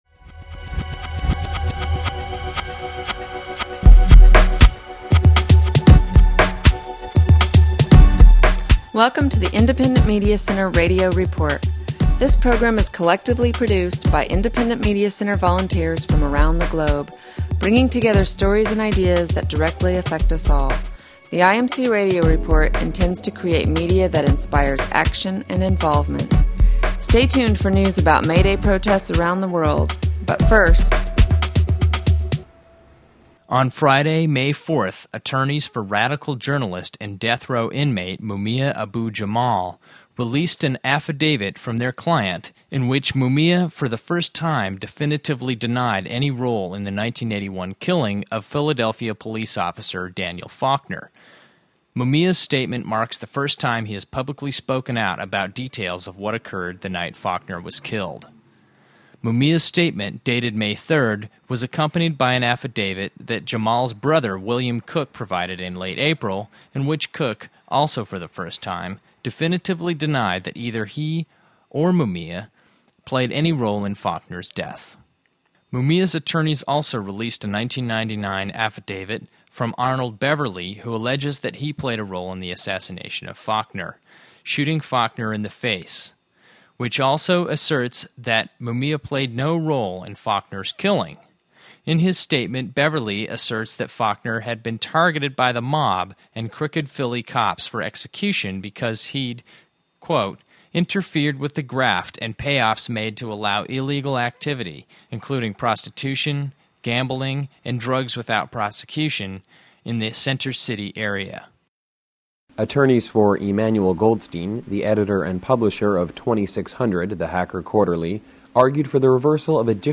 This 29:26 radio show features May Day events from Asia, the Americas, Europe and Australia.